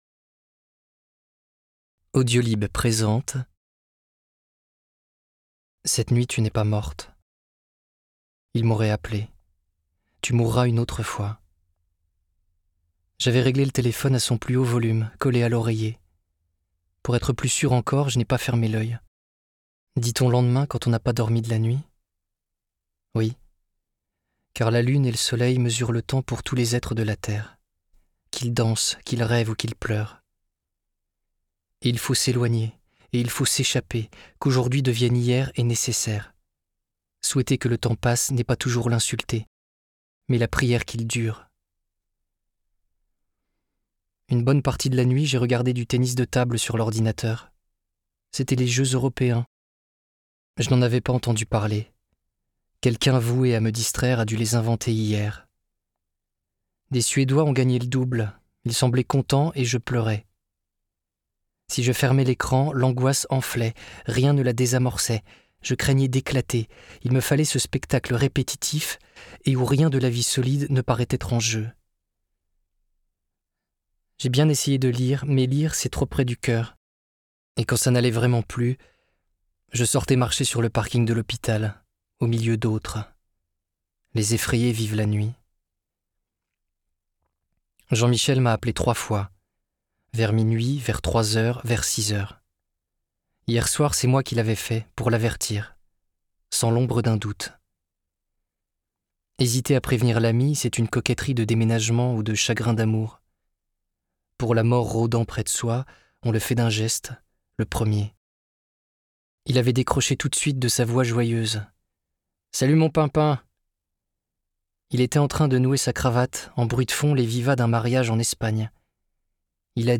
Interprétation humaine Durée : 09H25 21 , 95 € Ce livre est accessible aux handicaps Voir les informations d'accessibilité